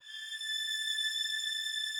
strings_081.wav